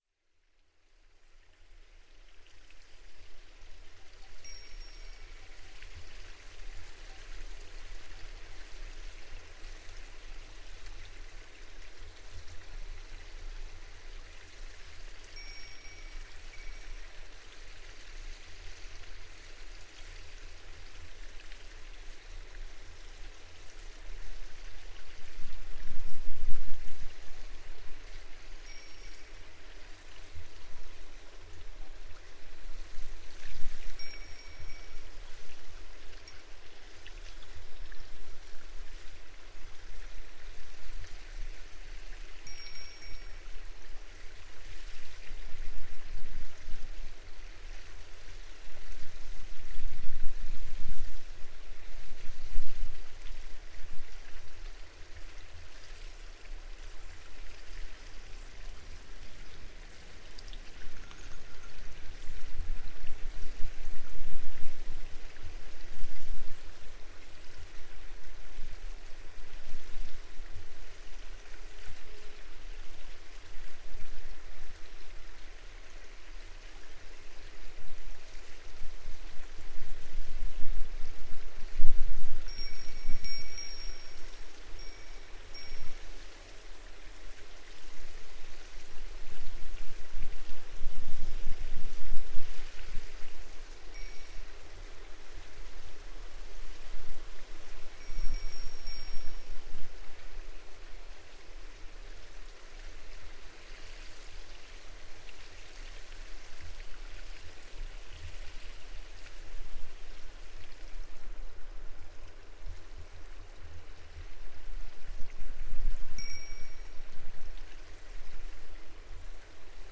Gravação do som de um rebanho de ovelhas procurando uma sombra. Gravado com Fostex FR-2LE e um par de microfones shotgun Rode NTG-2
Tipo de Prática: Paisagem Sonora Rural
Nodar-Ovelhas.mp3